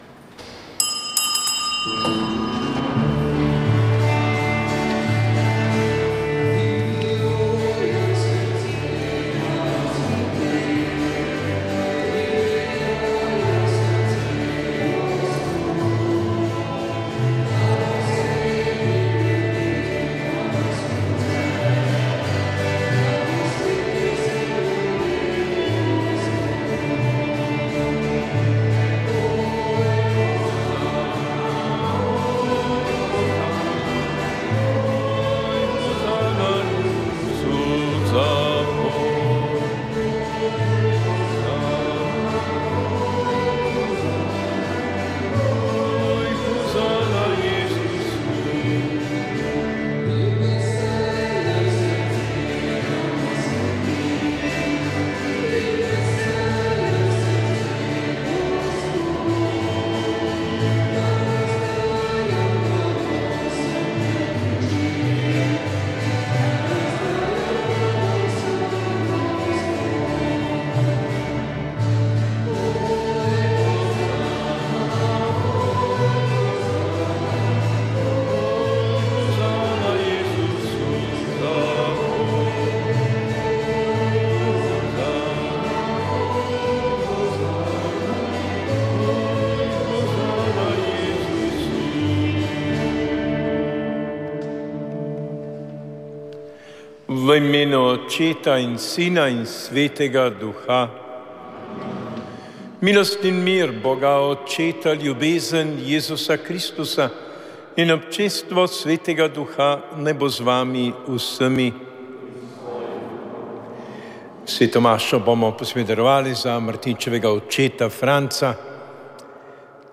Sveta maša